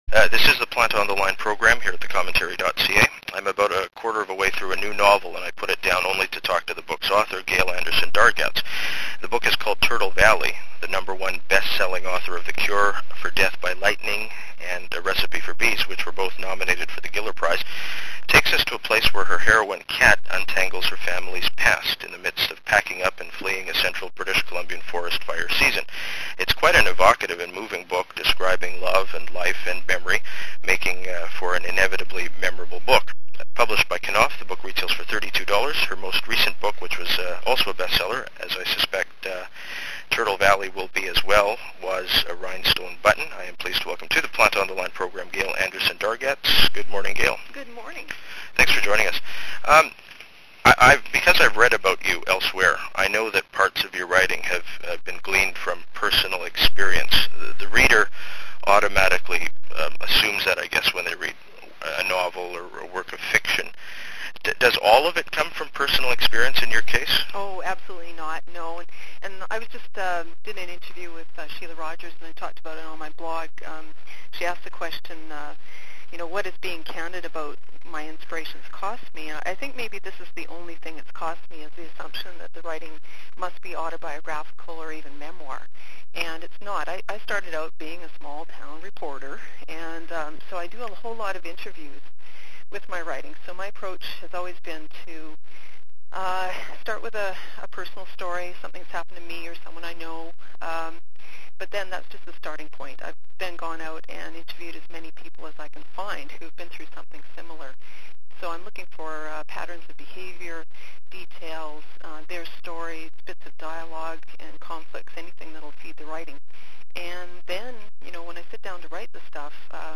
I’m about a quarter of a way through a new novel, and I put it down only to talk to the book’s author, Gail Anderson-Dargatz. The book is called Turtle Valley.